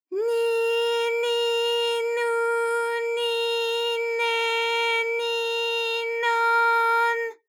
ALYS-DB-001-JPN - First Japanese UTAU vocal library of ALYS.
ni_ni_nu_ni_ne_ni_no_n.wav